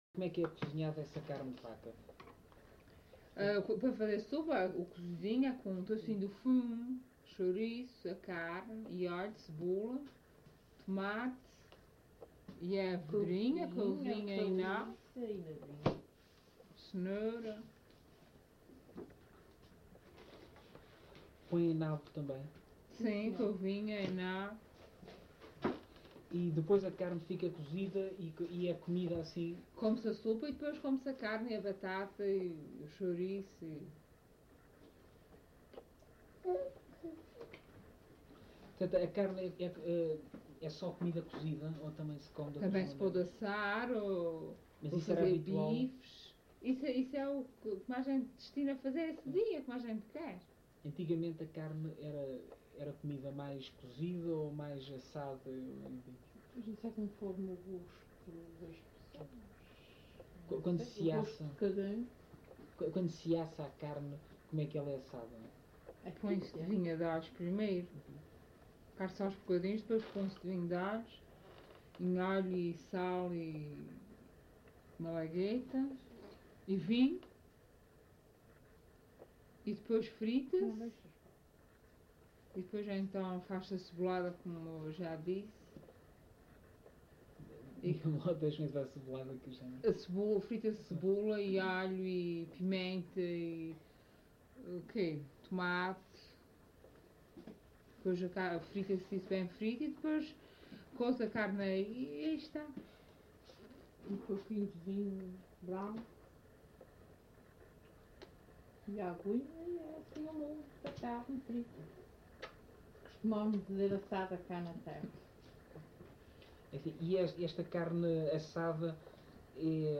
LocalidadeVila do Corvo (Corvo, Horta)